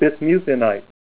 Name Pronunciation: Bismuthinite Synonym: Bismuth Glance Csiklovaite ICSD 201066 PDF 17-320 Bismuthinite Image Images: Bismuthinite Comments: Metallic, prismatic bismuthinite crystal topped with botryoidal marcasite.